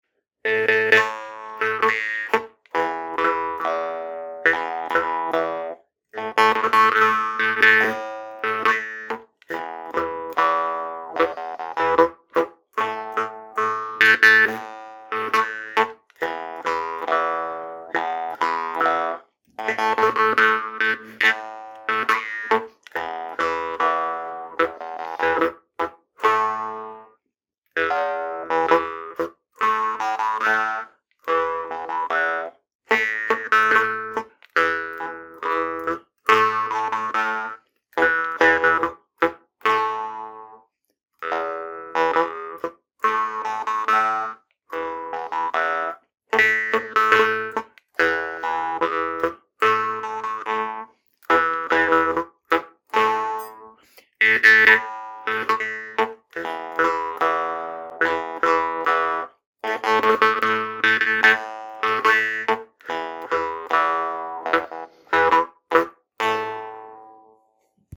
Typ Damme